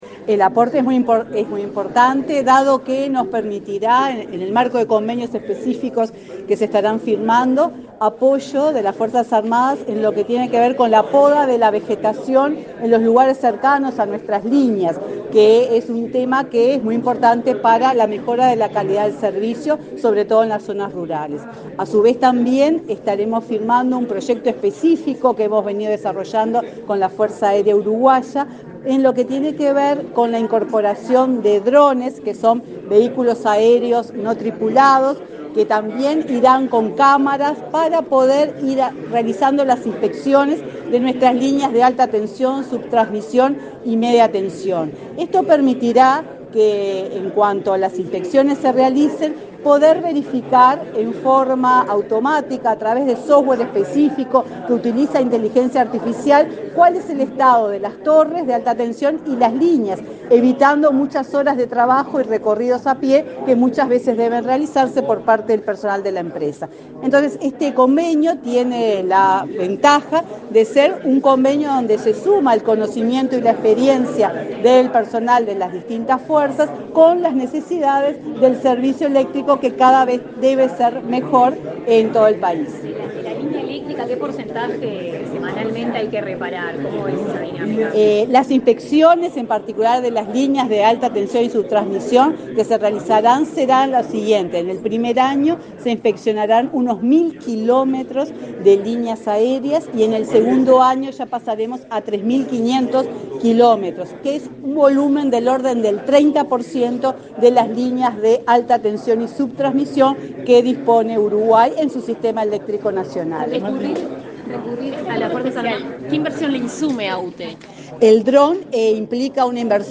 Declaraciones a la prensa de la presidenta de UTE, Silvia Emaldi
Declaraciones a la prensa de la presidenta de UTE, Silvia Emaldi 29/08/2022 Compartir Facebook X Copiar enlace WhatsApp LinkedIn Tras participar en la firma de convenio entre el Ministerio de Defensa Nacional y la UTE, este 29 de agosto, la presidenta de la empresa estatal efectuó declaraciones a la prensa.